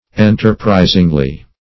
-- En"ter*pri`sing*ly, adv.
enterprisingly.mp3